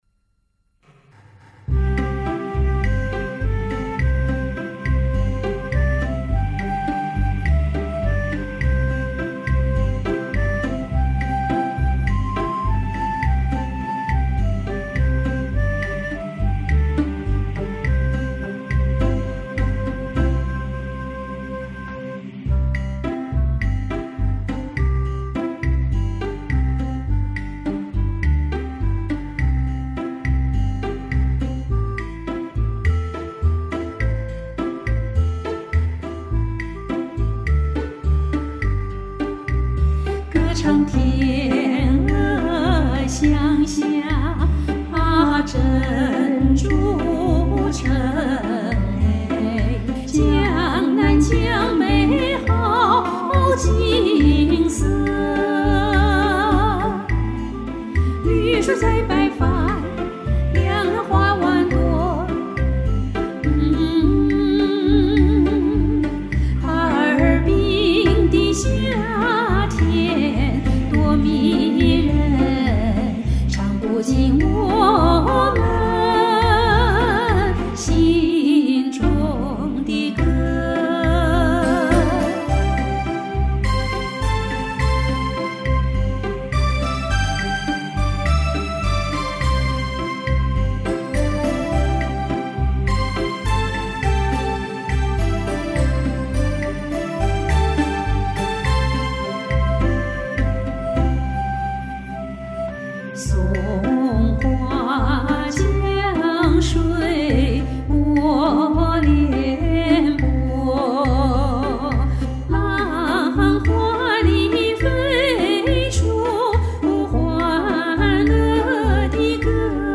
柔润入耳兼入心~~ ：）相得益彰~！
你俩声音一如既往的棒！和声奏鸣很搭！